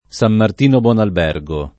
Sam mart&no bUqn alb$rgo] (Ven.), San Martino di Lupari [